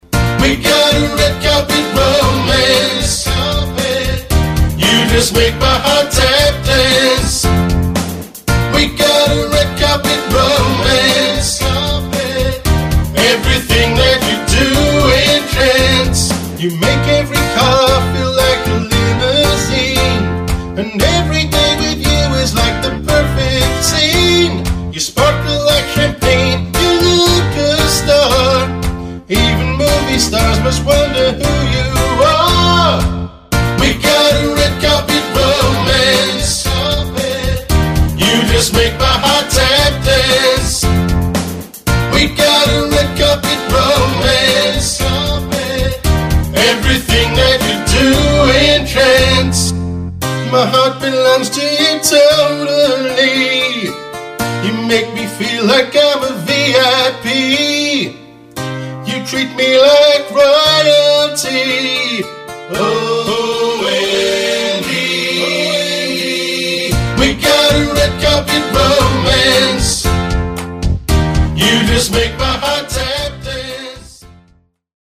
Pop/Dance